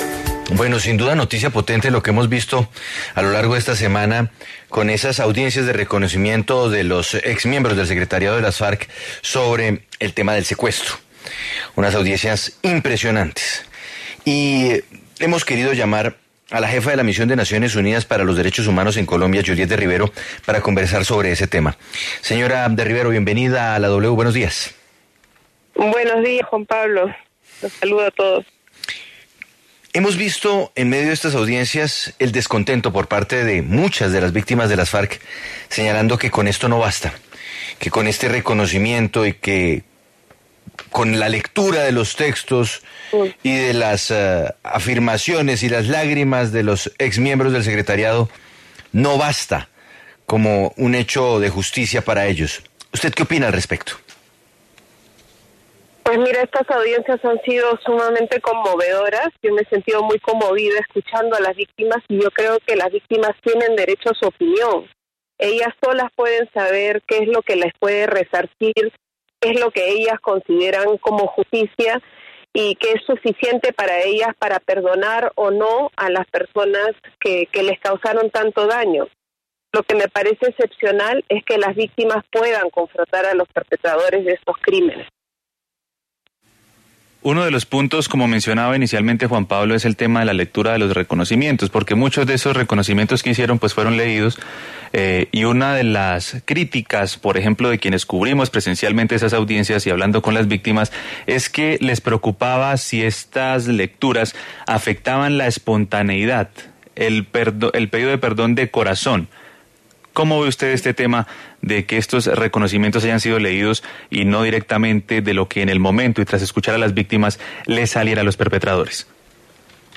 En entrevista con La W, la Jefe de la Oficina de Naciones Unidas en Colombia para los Derechos Humanos, Juliette de Rivero, se refirió a las audiencias de reconocimiento del antiguo secretariado de las Farc, señalando que las víctimas tienen derecho a su opinión de cara a la diversidad de opiniones sobre el reconocimiento de los excomandantes de las Farc por esos crímenes de guerra.